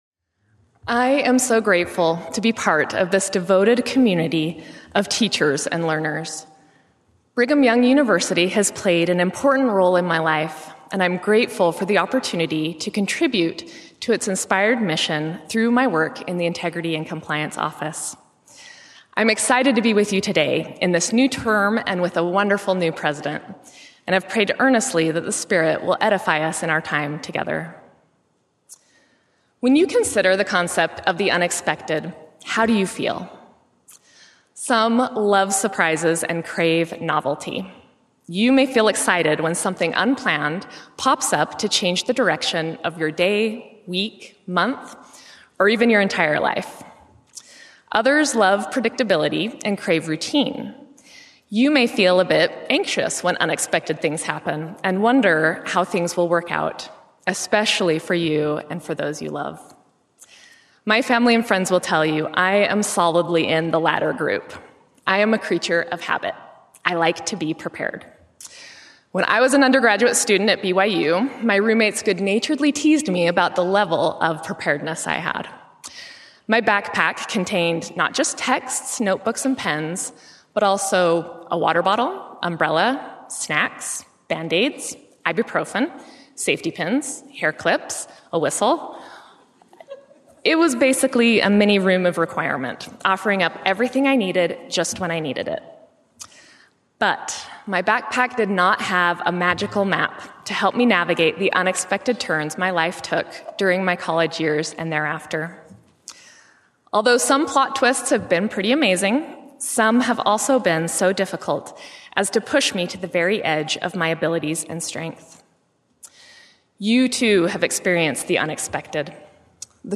Devotional